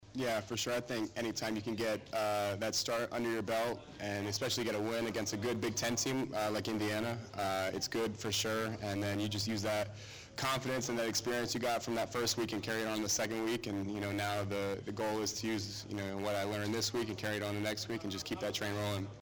AUDIO EXCERPTS FROM KYLE McCORD PRESS CONFERENCE